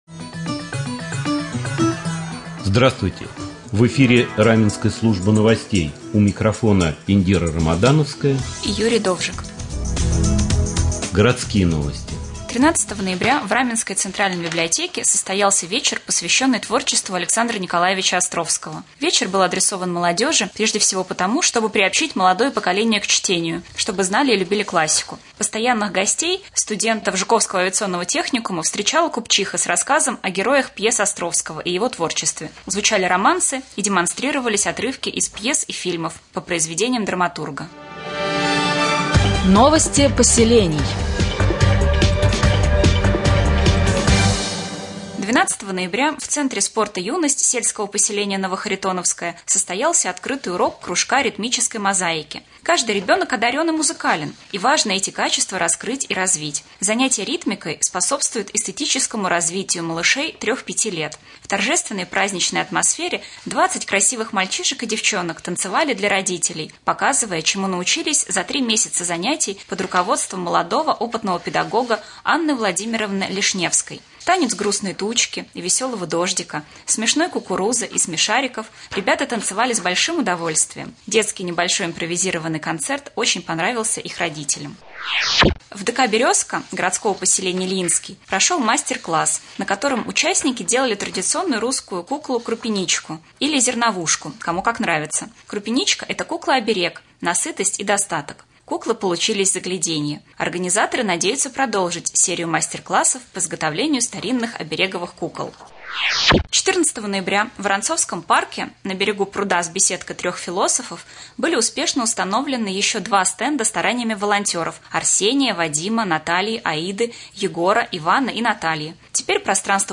1.13 ноября в студии Раменского телевидения состоялся брифинг с заместителем руководителя администрации Раменского района по вопросам ЖКХ Русланом Шахшаевым.